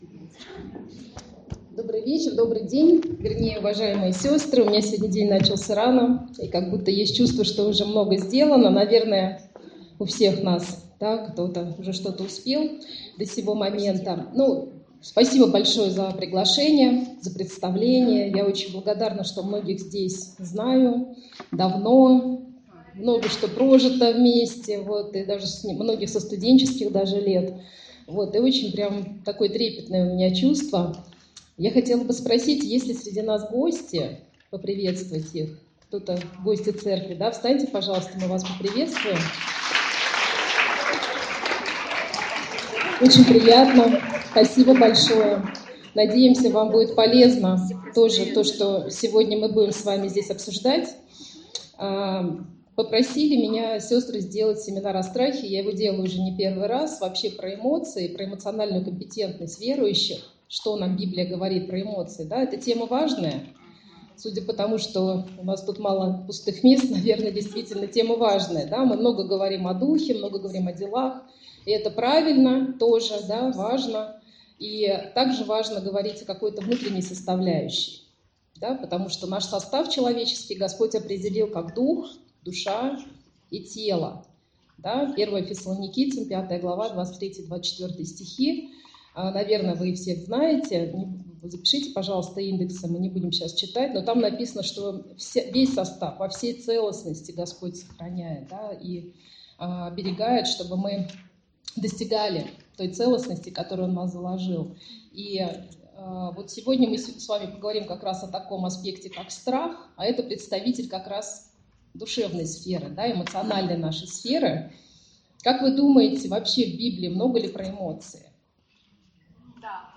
Семинар Для Женщин «Фактор Страха»